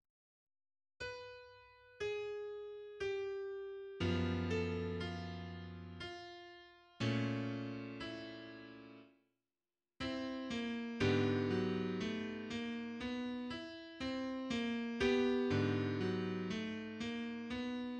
Atonality
Opening of Schoenberg's Klavierstück, Op. 11, No. 1, exemplifying his four procedures as listed by Kostka & Payne 1995
Avoidance of melodic or harmonic octaves, avoidance of traditional pitch collections such as major or minor triads, avoidance of more than three successive pitches from the same diatonic scale, and use of disjunct melodies (avoidance of conjunct melodies).[29]